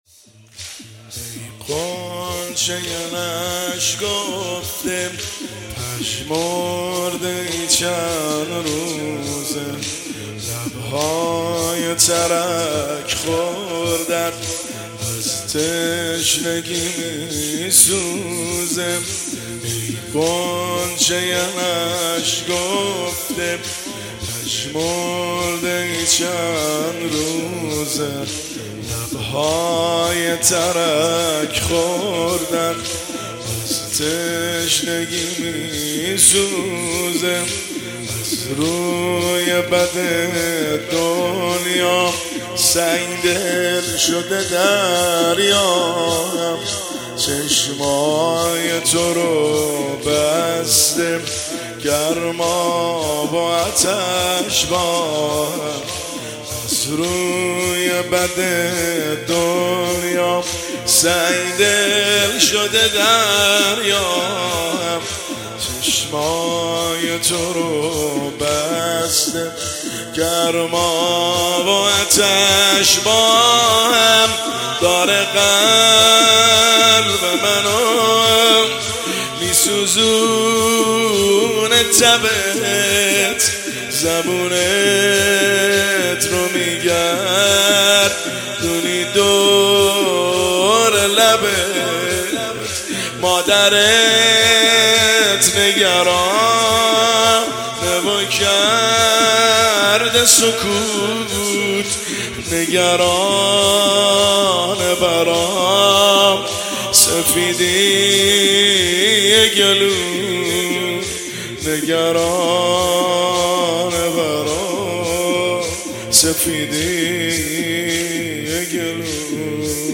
زمینه – شب هفتم محرم الحرام 1404